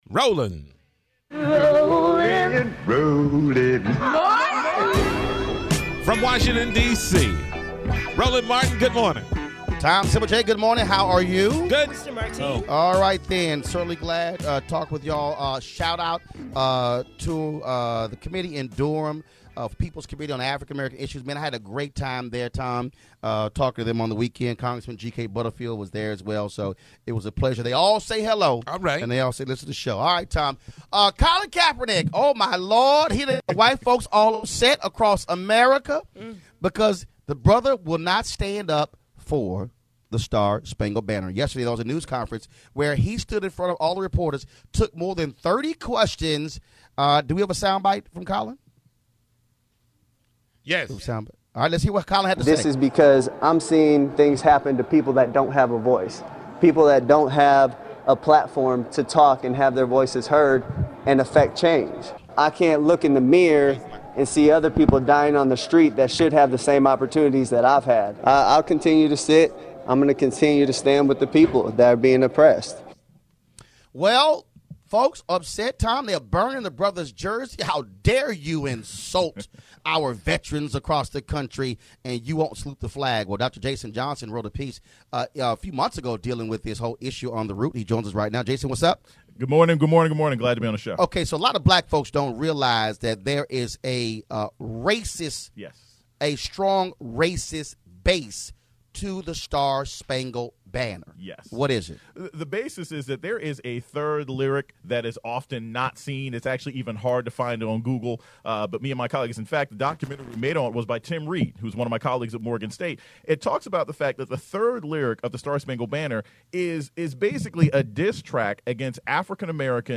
8/29/16- Roland Martin talks to Dr. Jason Johnson about Colin Kaepernick‘s decision to not stand during the National Anthem and the history of the song’s racist history.